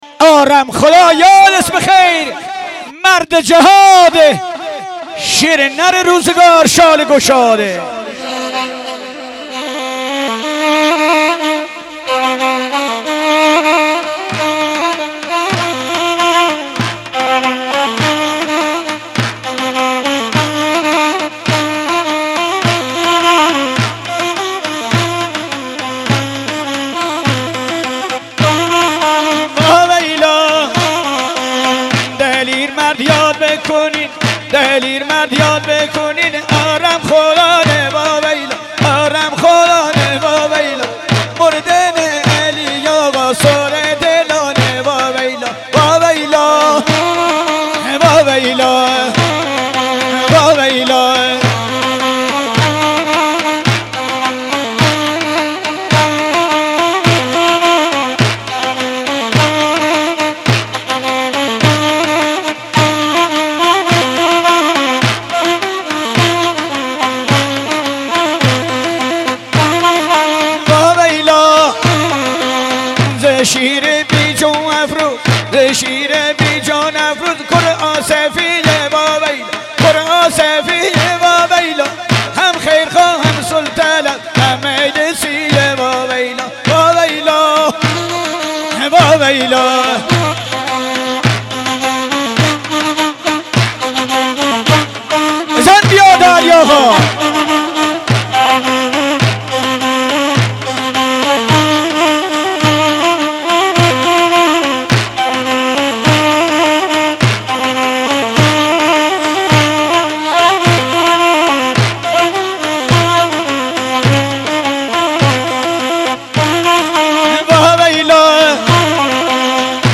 عزاداری و سوگواری لری بختیاری
همراه با نوای سوزناک نی و دهل چپ
سبک ها: دندال (دوندال)، گاگریو – گویش: بختیاری